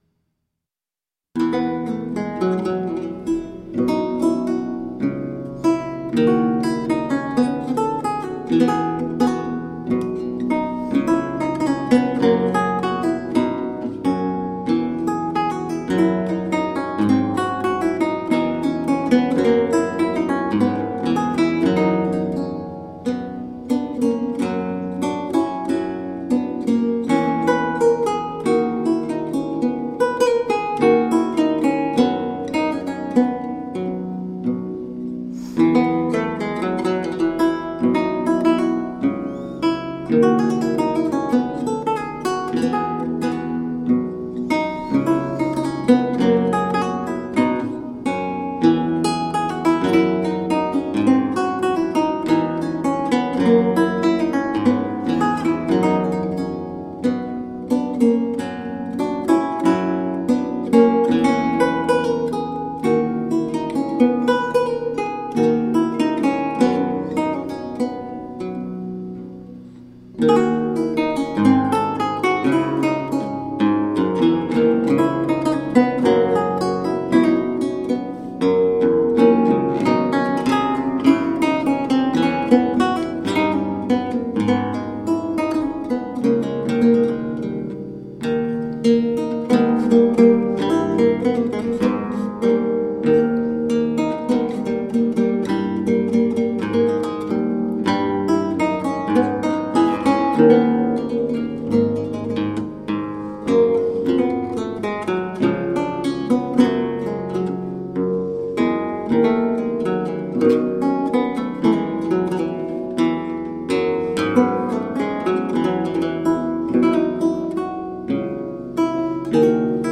Lute sonatas from mozart's time.
Classical, Baroque, Classical Period, Instrumental